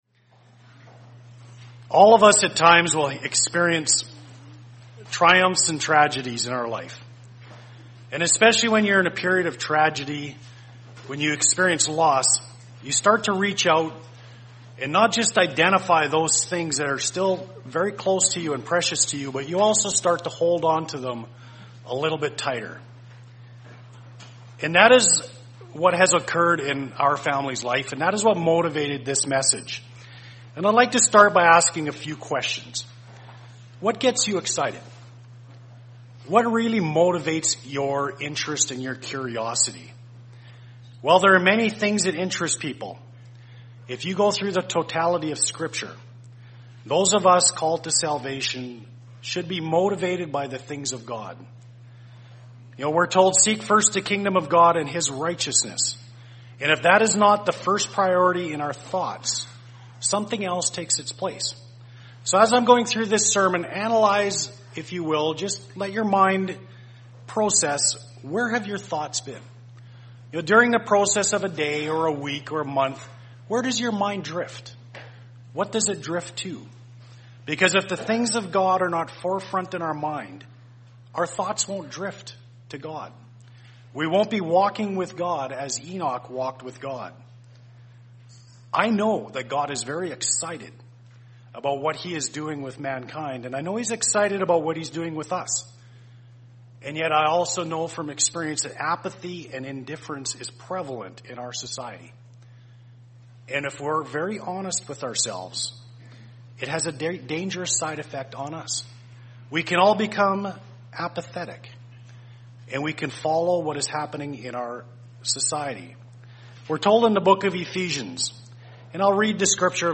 First split sermon on Saturday, August 9, 2014 in Spokane, Washington. Zeal is a necessary component of our Christian lives.